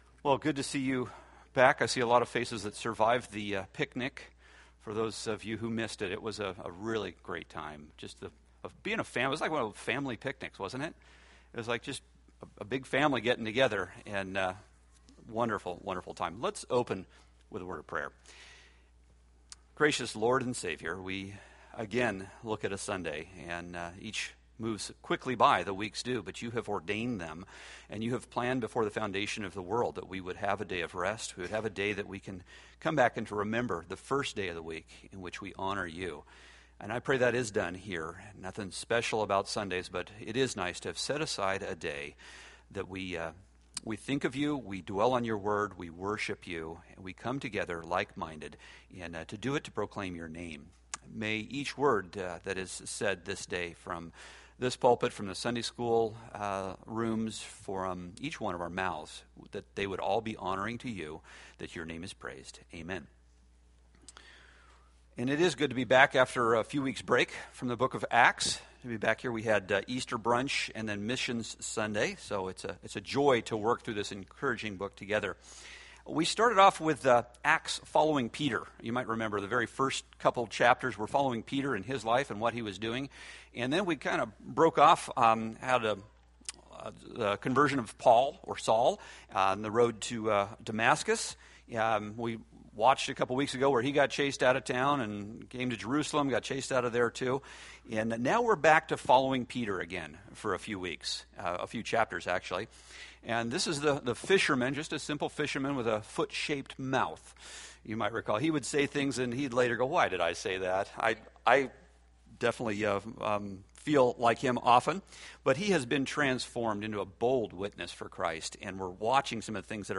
Date: May 4, 2014 Series: Acts Grouping: Sunday School (Adult) More: Download MP3